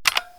key-press-4.wav